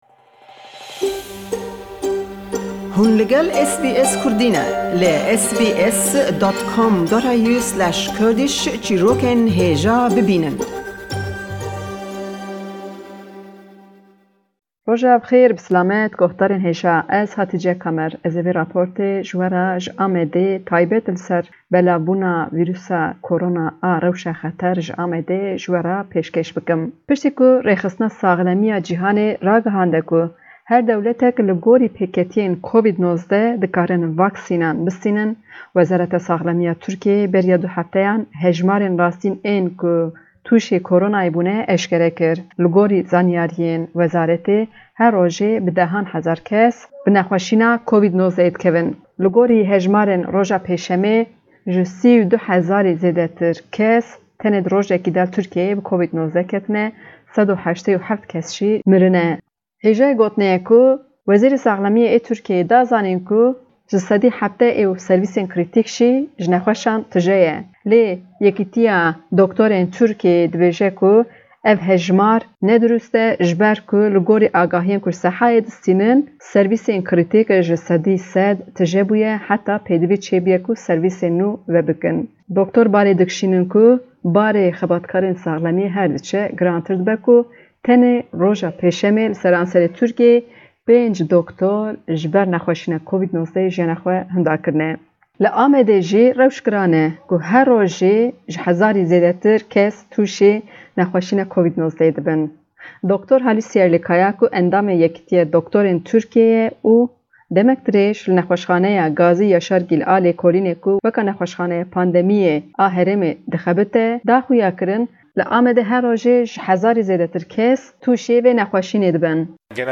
Raporta vê heftê ji Amedê li ser rewşa dawî ya COVID-19 li Tirkiyê bi giştî ye.